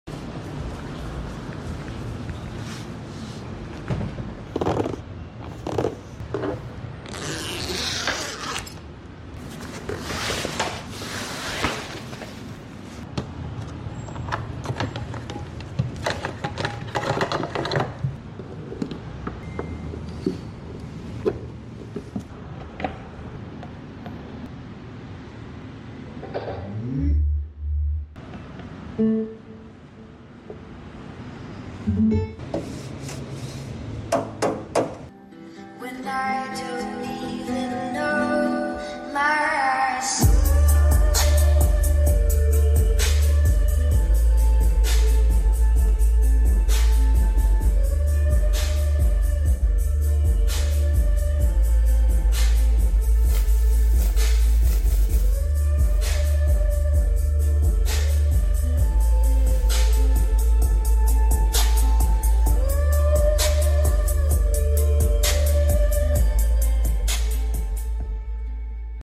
JBL PartyBox Ultimate Sound Test: sound effects free download
Mp3 Sound Effect JBL PartyBox Ultimate Sound Test: Power, Bass & Clarity Unleashed!